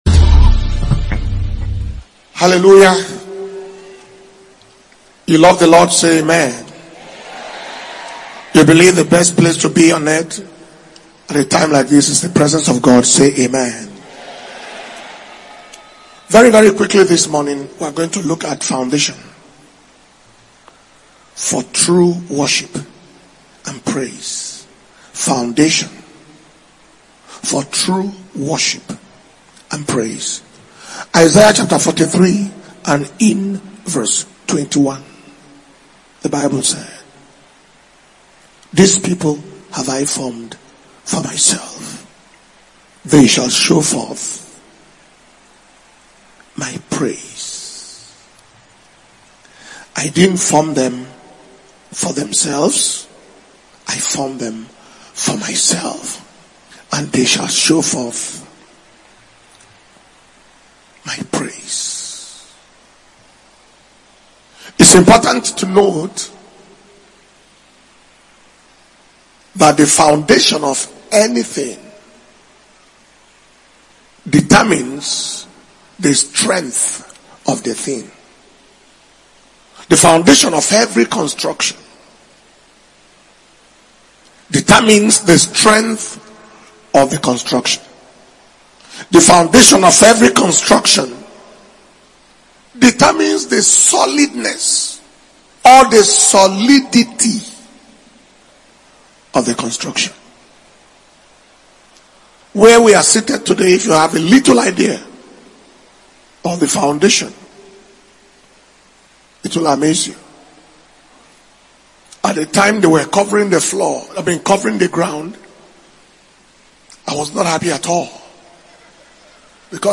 June 2025 Impartation Service - Sunday June 15th 2025